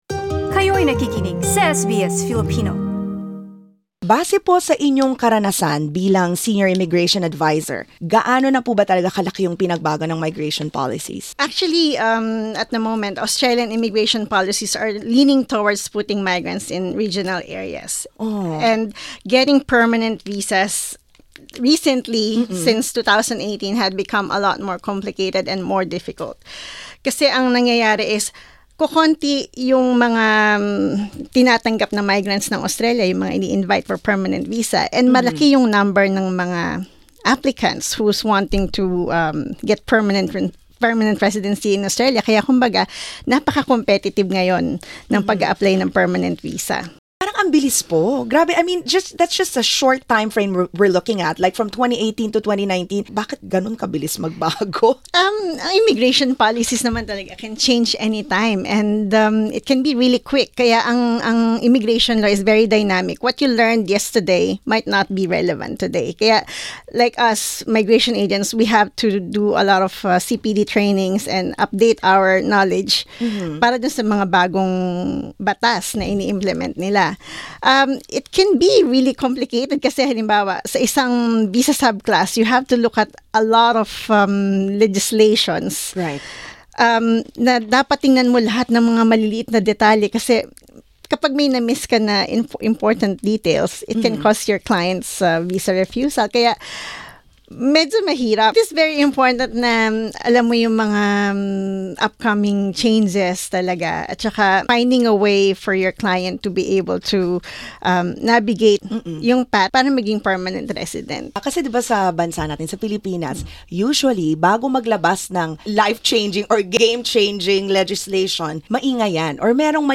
What options are available to permanent resident hopefuls? SBS Filipino sat down with an expert and discussed ways to navigate the fast-changing landscape of Australia's migration program.